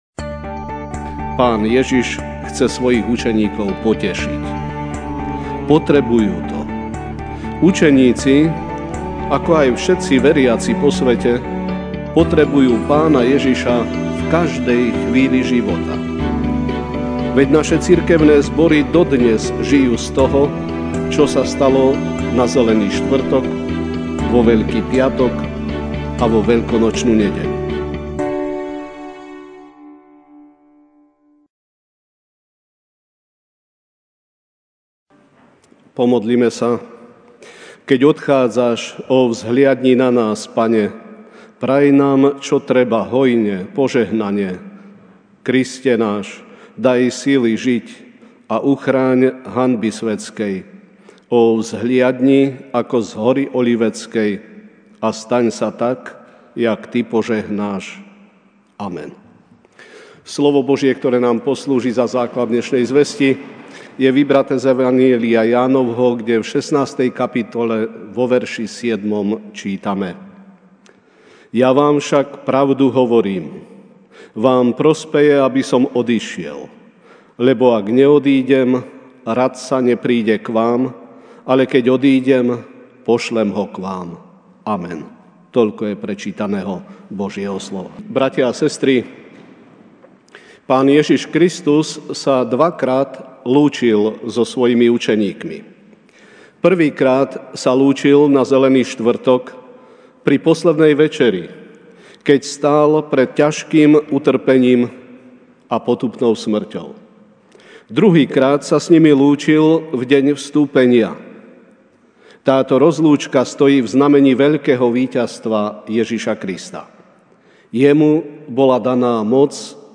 Večerná kázeň: Vstúpenie Pána (J 16, 7) Ja vám však pravdu hovorím: Vám prospeje, aby som odišiel; lebo ak neodídem, Radca nepríde k vám; ale keď odídem, pošlem Ho k vám.